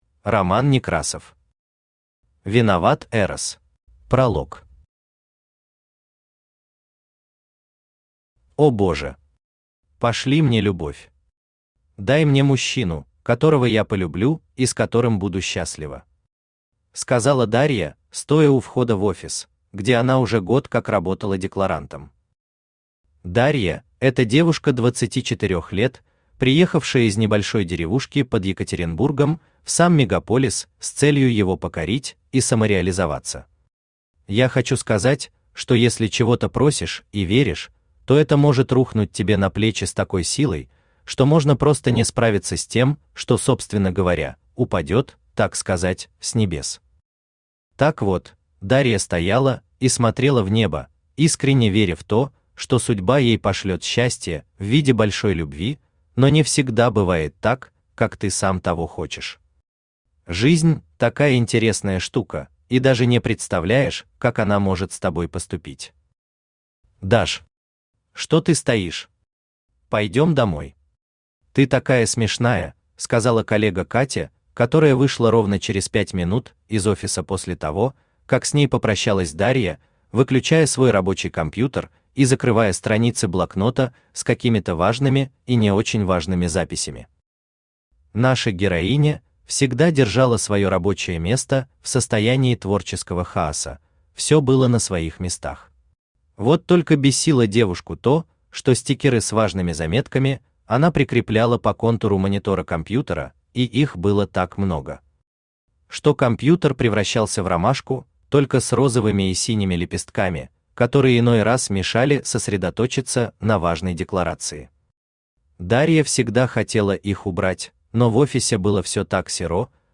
Aудиокнига Виноват Эрос Автор Роман Андреевич Некрасов Читает аудиокнигу Авточтец ЛитРес.